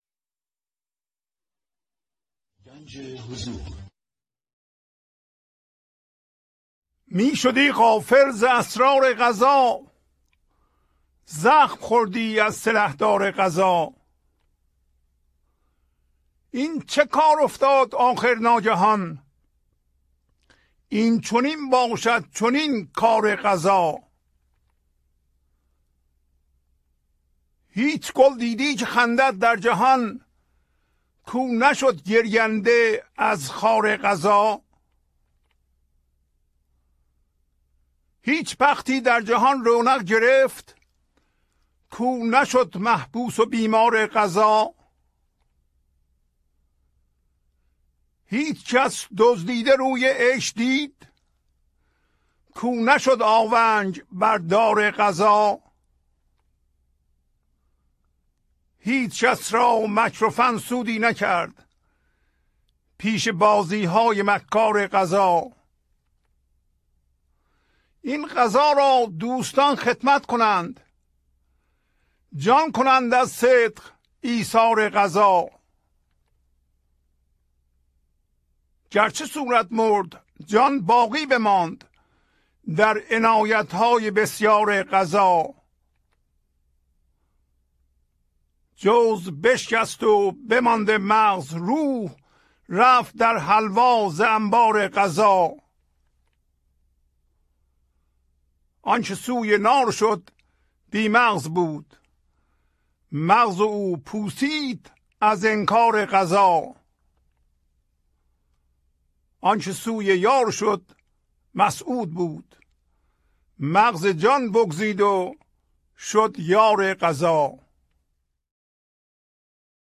1027-Poems-Voice.mp3